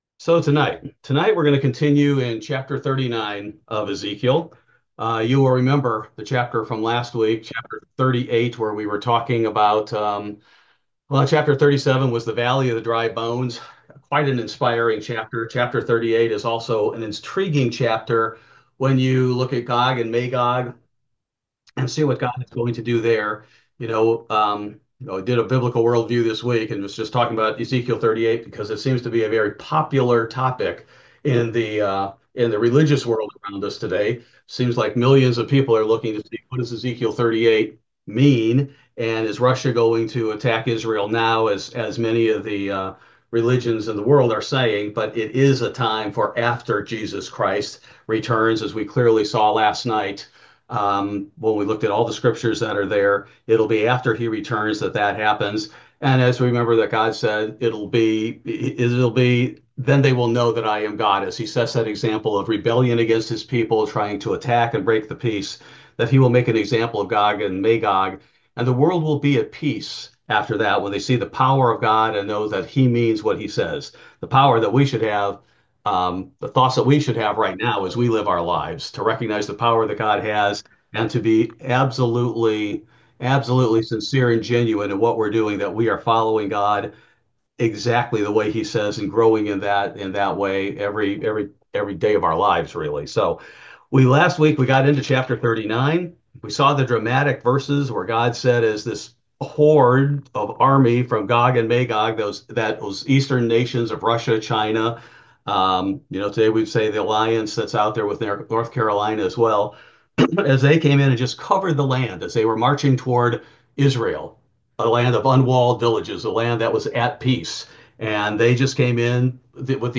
This Bible study focuses primarily on Ezekiel 39 and Intro to Millennial Temple